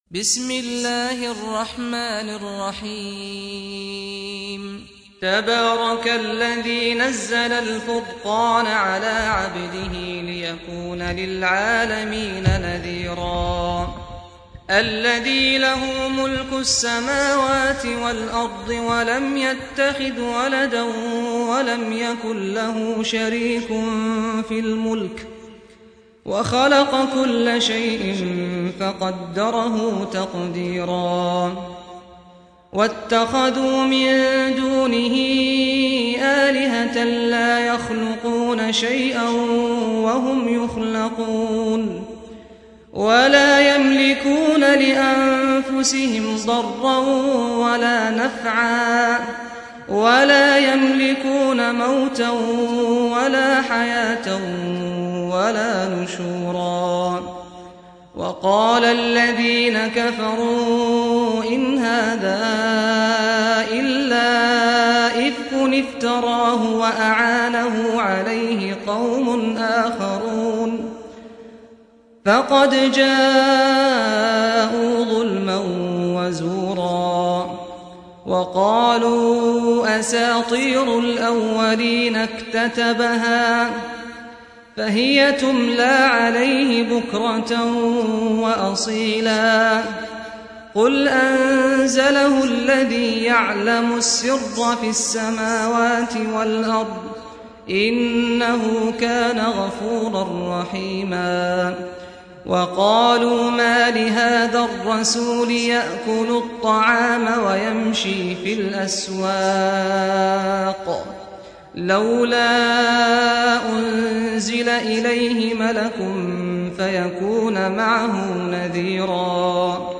سُورَةُ الفُرۡقَانِ بصوت الشيخ سعد الغامدي